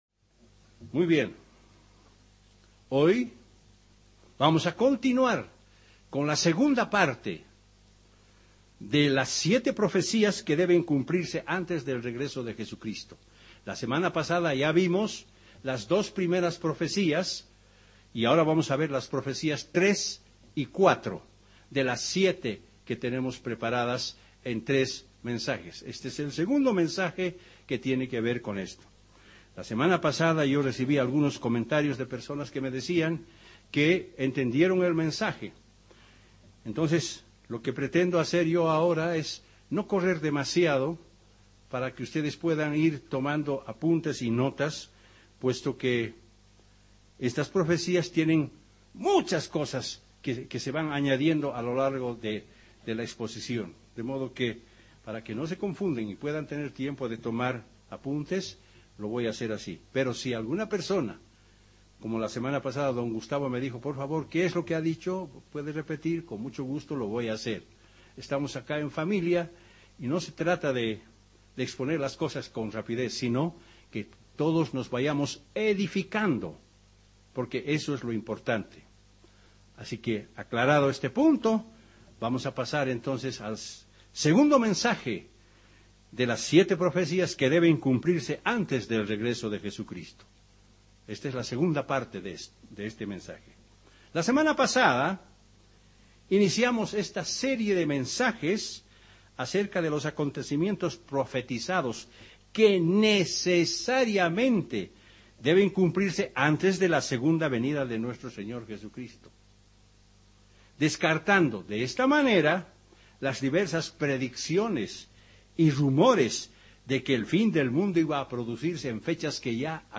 ¿Cuál es el escenario y eventos que deben cumplirse para el retorno de Jesucristo? En este sermón analizaremos el libro de Daniel, capítulos 7 y 8.
Given in La Paz